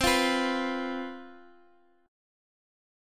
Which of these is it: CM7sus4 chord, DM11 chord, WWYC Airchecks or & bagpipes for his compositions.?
CM7sus4 chord